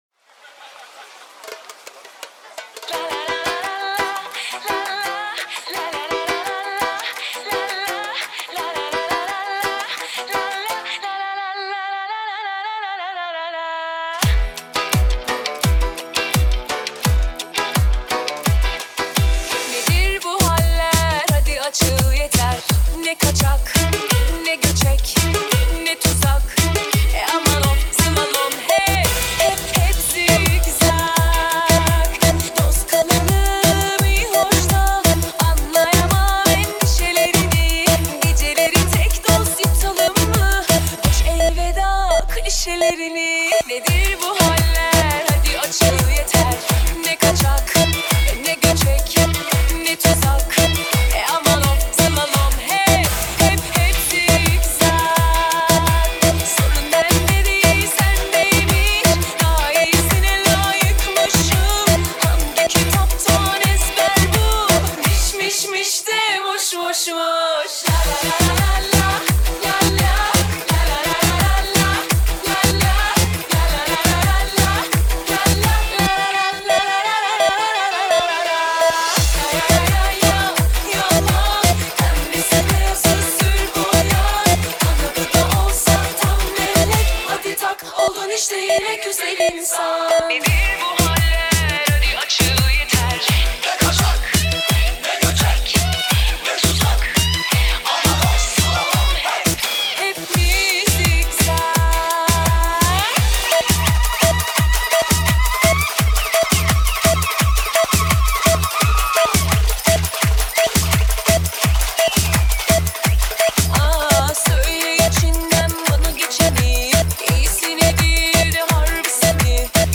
آهنگ ترکیه ای آهنگ شاد ترکیه ای آهنگ هیت ترکیه ای ریمیکس
دانلود نسخه ریمیکس همین موزیک